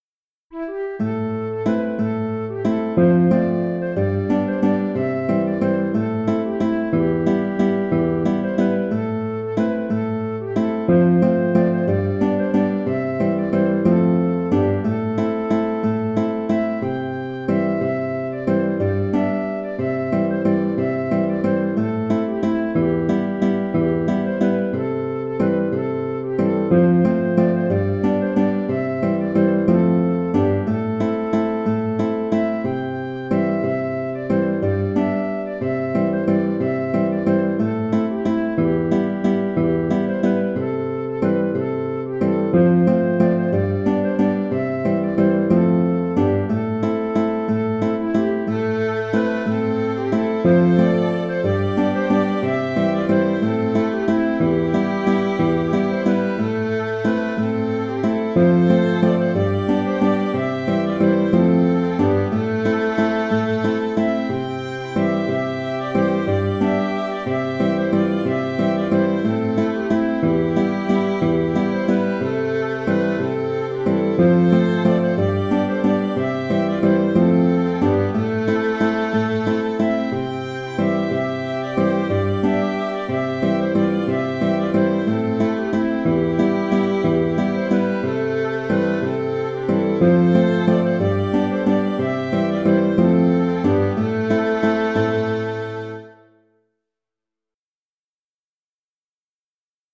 Valse irlandaise 1 (Valse écossaise) - Musique irlandaise et écossaise
Encore un morceau sans titre, mais c'est une mélodie bien connue des amateurs de traditionnel irlandais.
Je l'ai adapté pour la danser en valse écossaise, danse bien appréciée des connaisseurs.